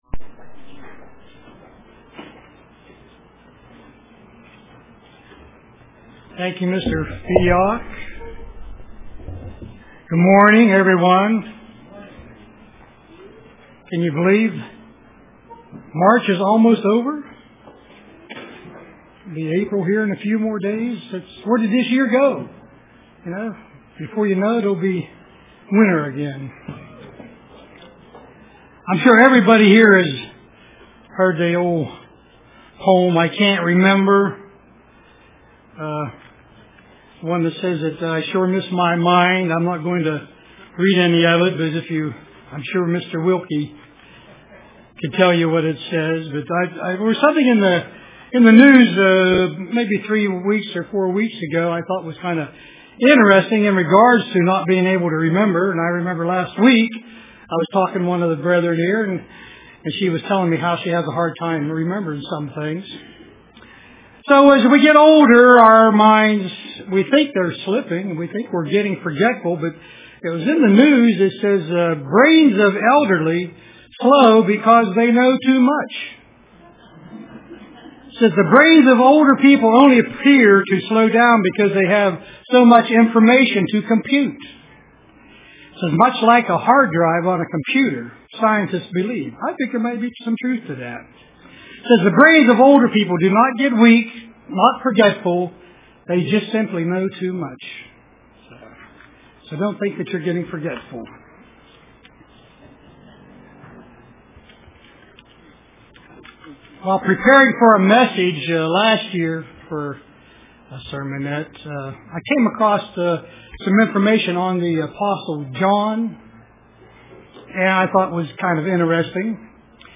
Print By This We Know UCG Sermon